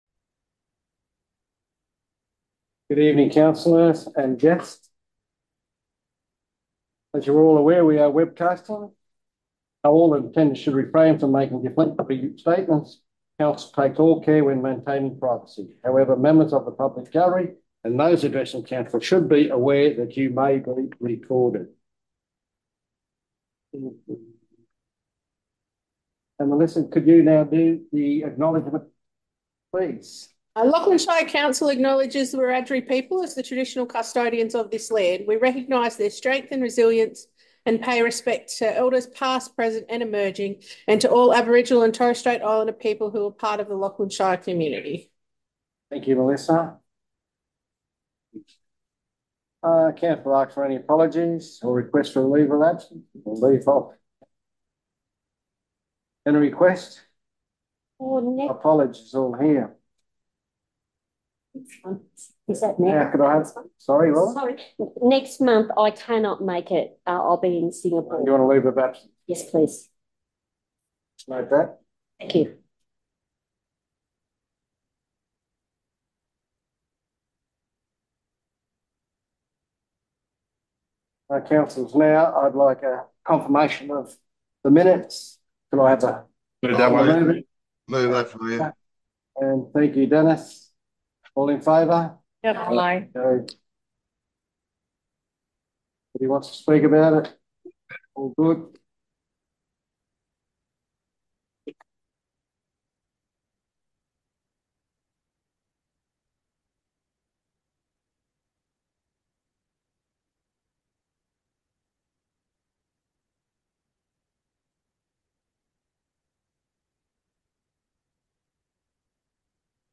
The July 2024 meeting will be held in the Council Chambers at 2:00pm and is open to the public.